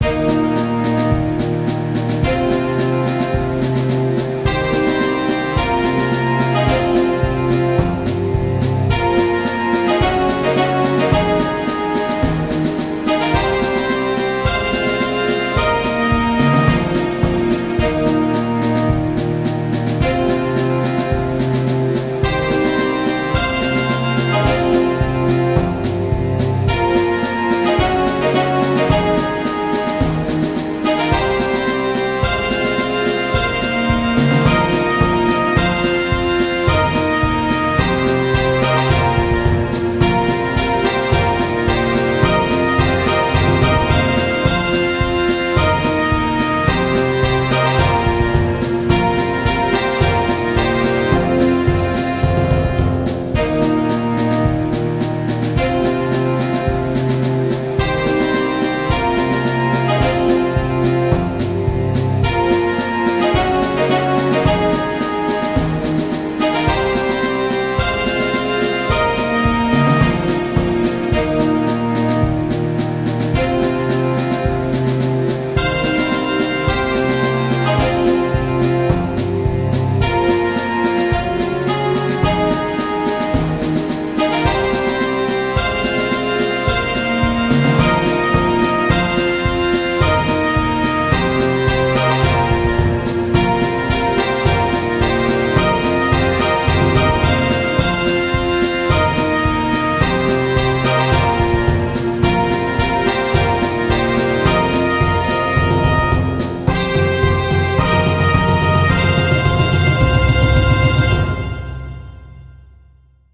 Intended to be like a national antem of a very evil asian country (that you're most likely fighting). I am terrible at orchestral arrangements, plus, my orchestra samples suck, so it's take it or leave it i guess.